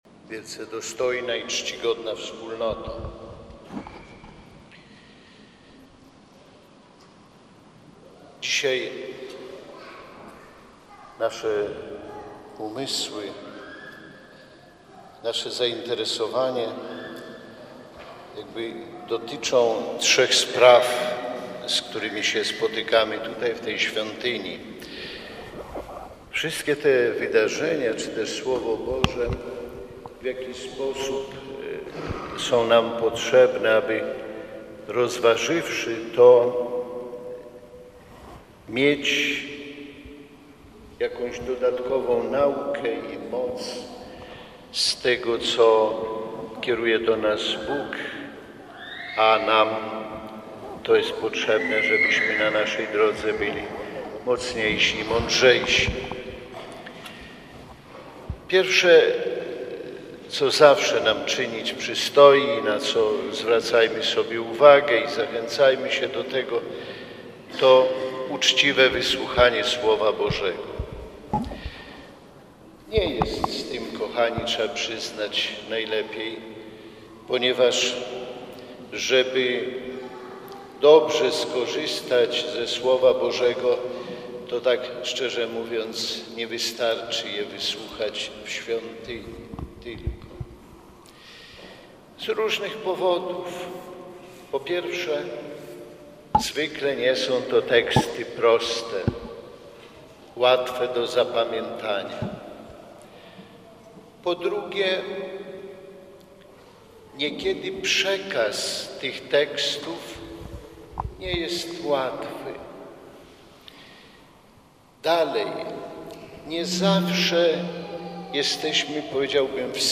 Uroczystą sumę z tej okazji odprawił biskup warszawsko-praski Romuald Kamiński.
homilia-Faustyny.mp3